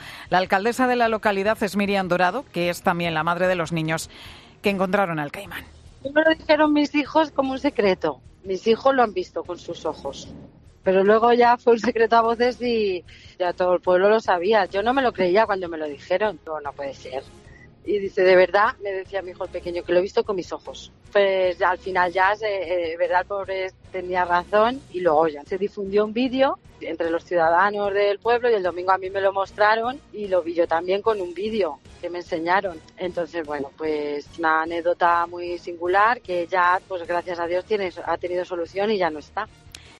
AUDIO: Escucha aquí las palabras de la alcaldesa de un pueblo toledano sobre el animal que localizaron en una balsa